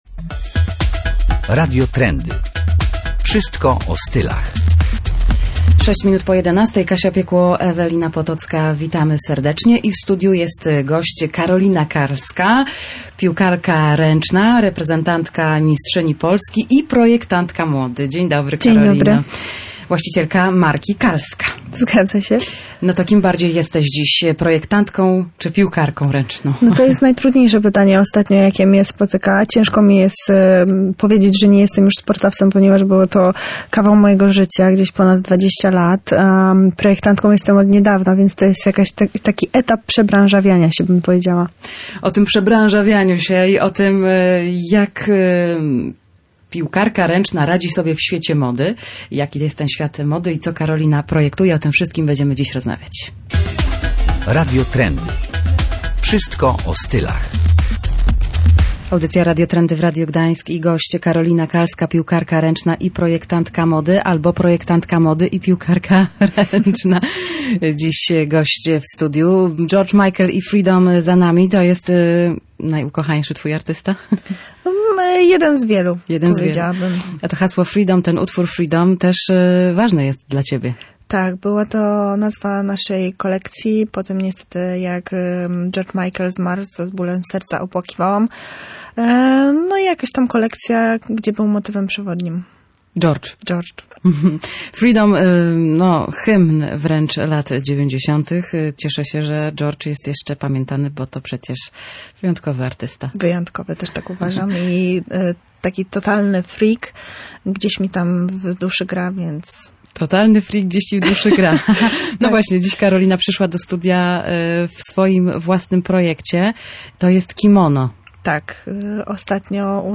Zobaczymy, co przyniesie przyszłość – opowiada w studiu Radia Gdańsk